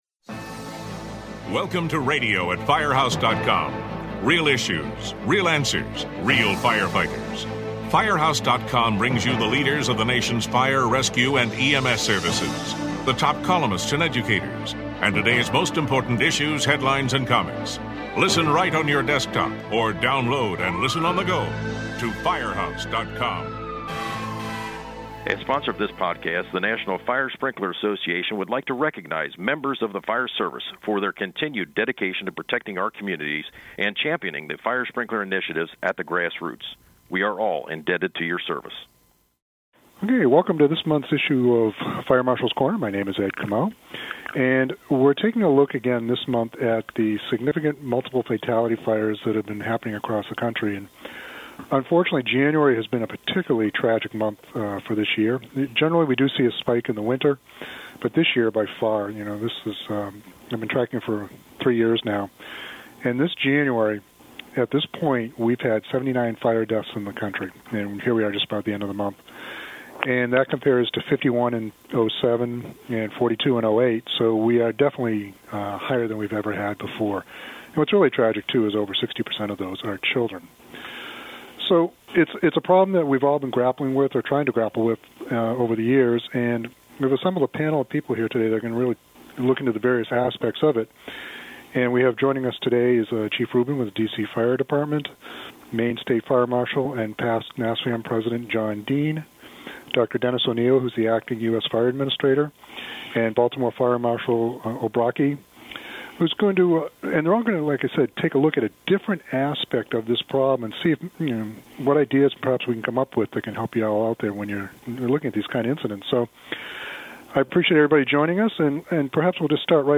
His guests include: • Dr. Denis Onieal, acting USFA Fire Administrator, discusses how the changing demographics in the United States are having an impact on the fires and the fire service.